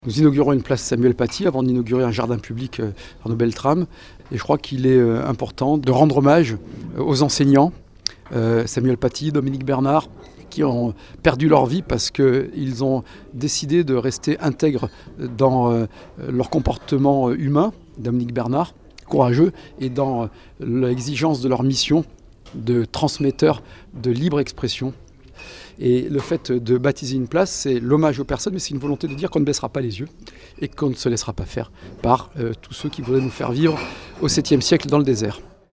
Un acte fort, pour David Lisnard, le maire de Cannes